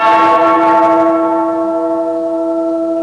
Bell Intro Sound Effect
Download a high-quality bell intro sound effect.
bell-intro.mp3